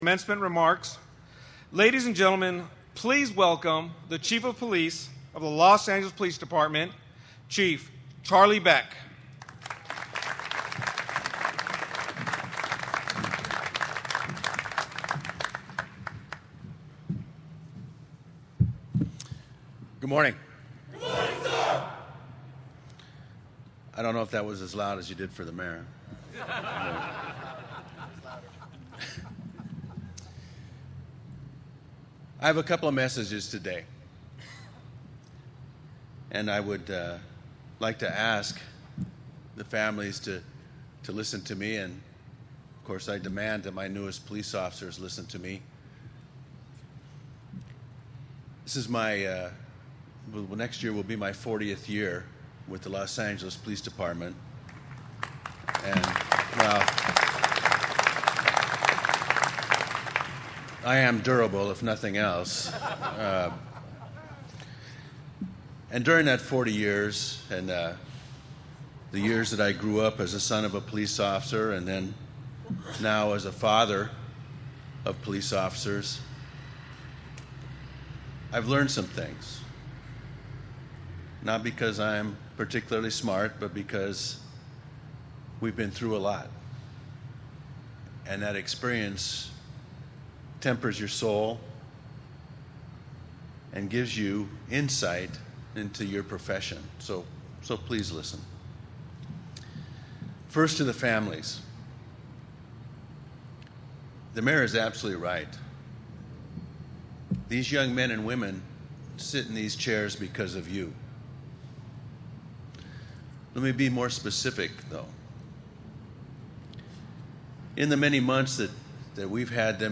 Los Angeles: October 31, 2024 – A Los Angeles Police Academy graduation ceremony was held this morning at the LAPD Administration Building plaza.
Upholding long-standing Department traditions at the end of the ceremony, recruits vigorously recited their class chant and tossed their caps high into the air, symbolizing their triumph at the academy and the promise of success as LAPD’s newest officers.